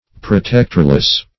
Protectorless \Pro*tect"or*less\, a.